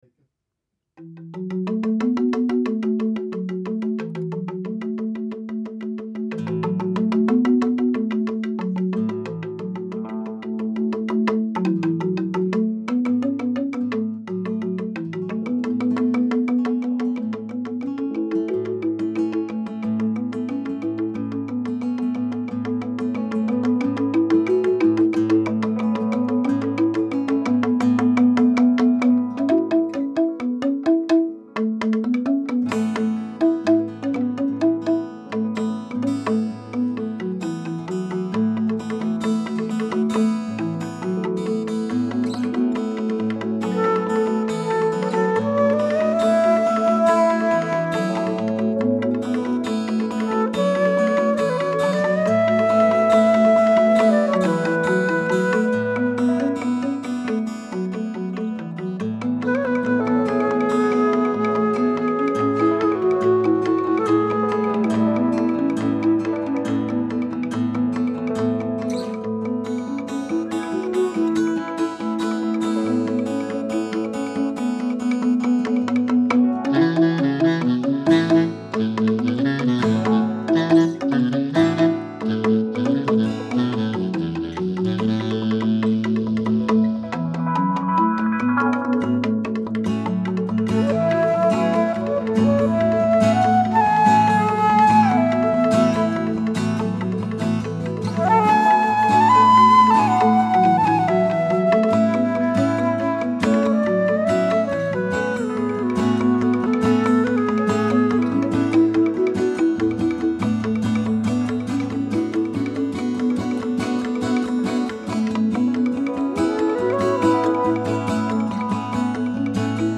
Gesang und Gitarre:
Saxophon und Querflöte:
Vibraphon und Tinklit: